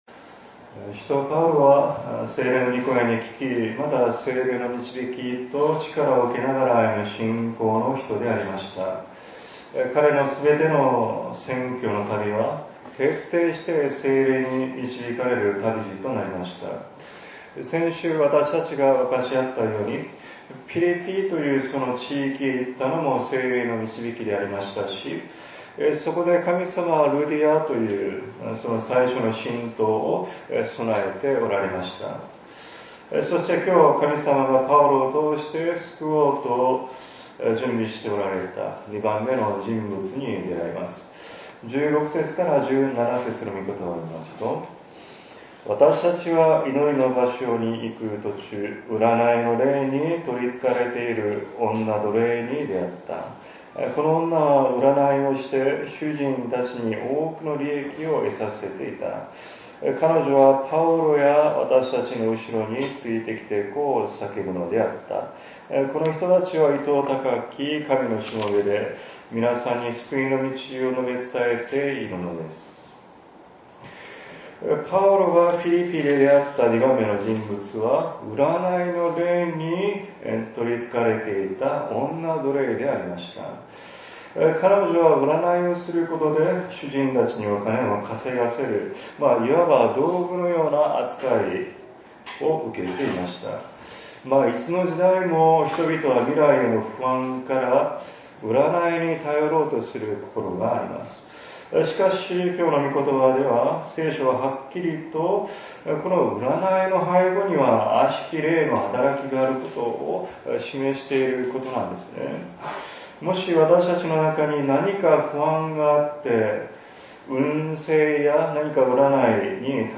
アドベント第３主日＜午前１０時３０分より＞
説 教 「イエスの御名の力」